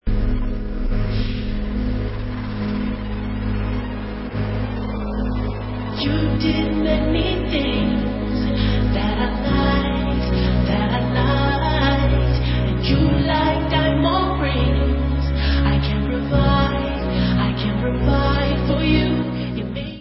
hrající fantastický R&B pop.